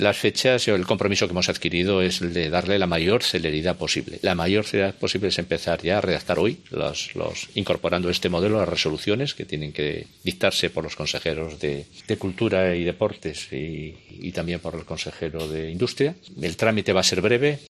Juan Cofiño: Las ayudas se convocarán con la mayor celeridad posible